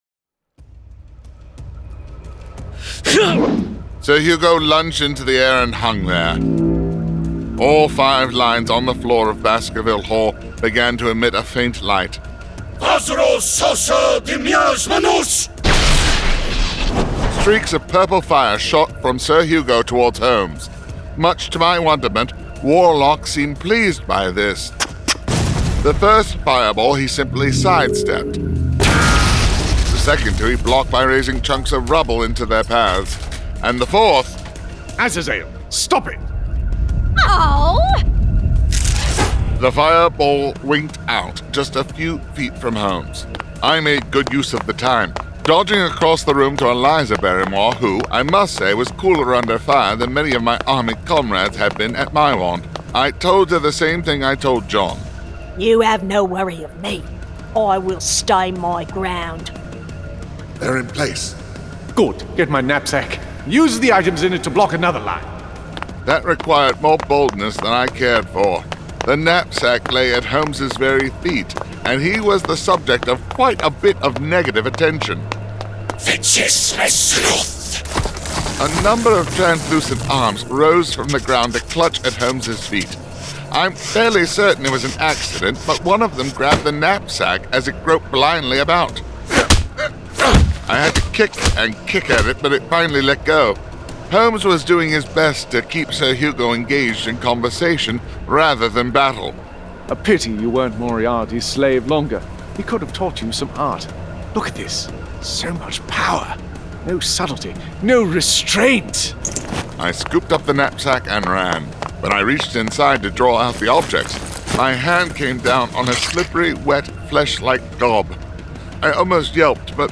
This is an actively updated collection of graphic audio material.